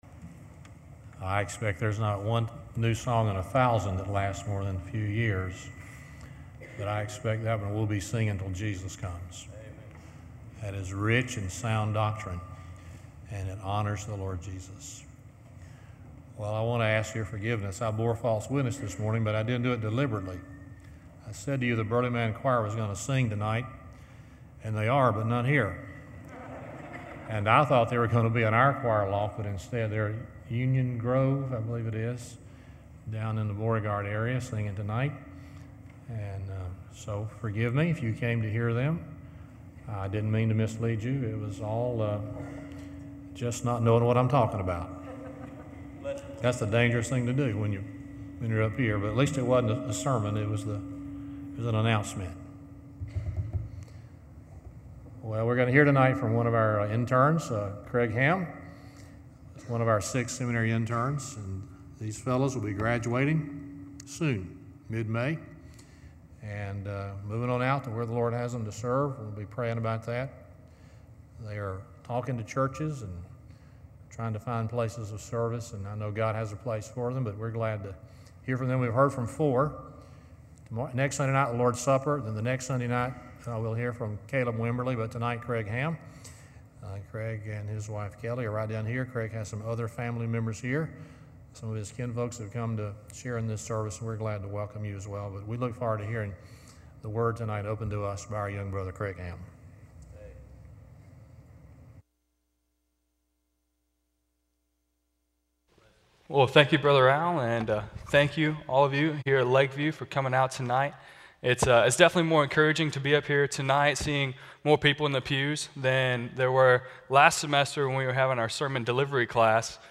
Seminary Intern Sermon Date